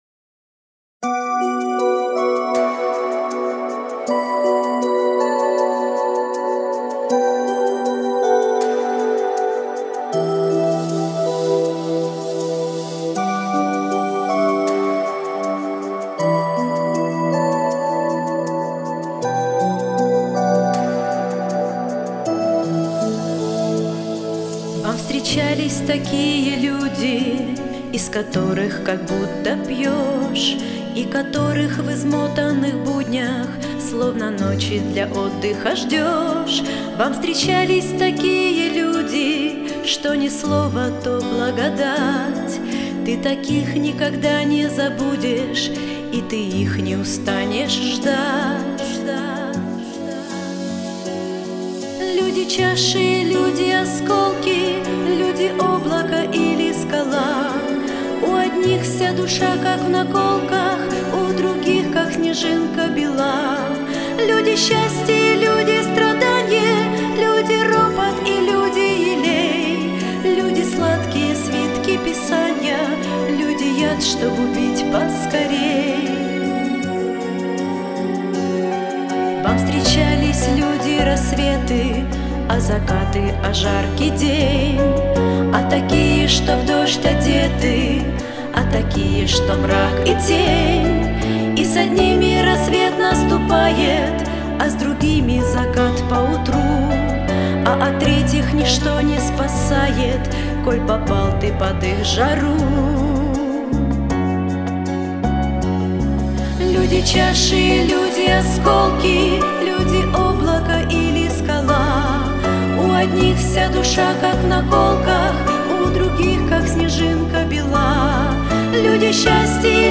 песня
207 просмотров 221 прослушиваний 18 скачиваний BPM: 105